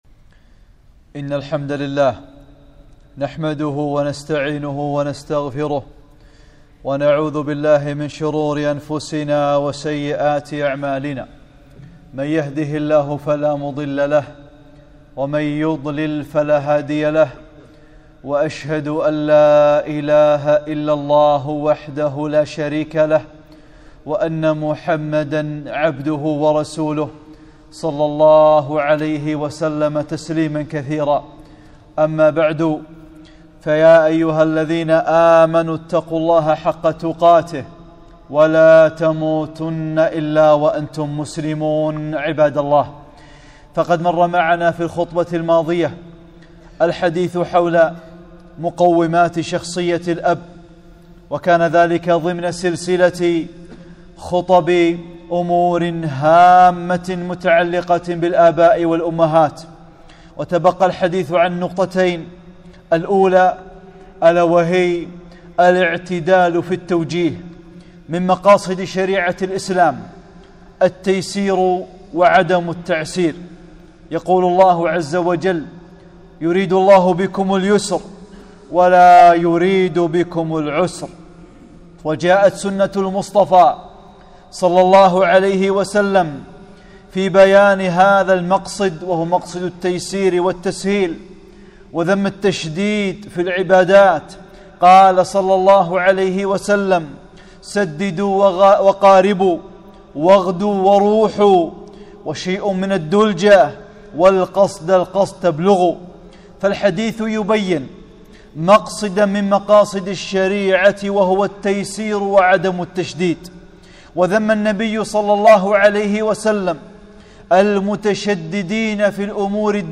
(23) خطبة - الاعتدال في التوجيه والدعاء